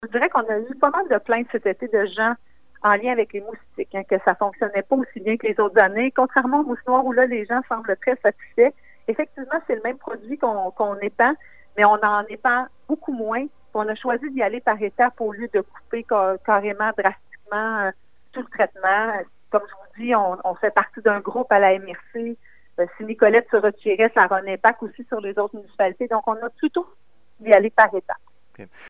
Au terme de la séance du conseil municipal lundi soir, la mairesse Geneviève Dubois a affirmé que la décision repose sur une démarche sérieuse des élus, qui se sont beaucoup documentés.
Madame Dubois ajoute que les moustiques sont une nuisance qui se manifeste surtout le soir alors que les mouches noires sont dérangeantes toute la journée: